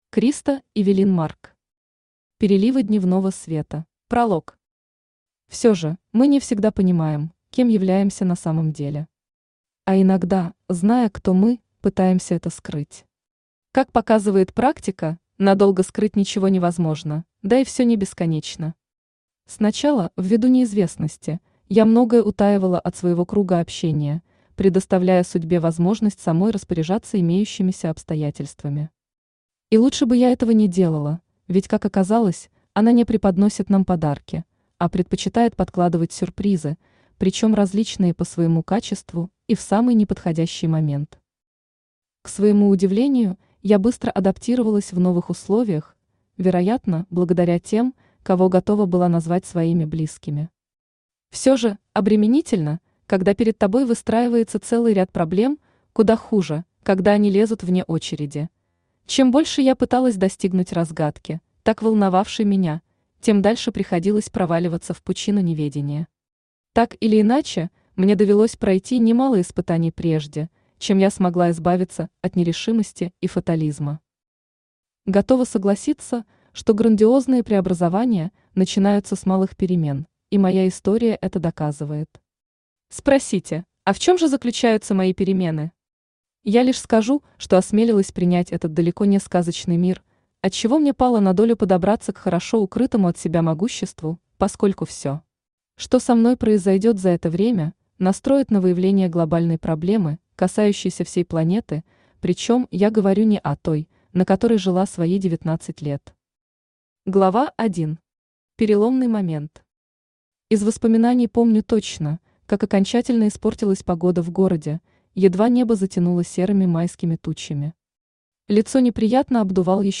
Аудиокнига Переливы дневного света | Библиотека аудиокниг
Aудиокнига Переливы дневного света Автор Криста Эвелин Марк Читает аудиокнигу Авточтец ЛитРес.